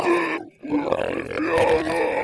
zombie_voice_idle4.wav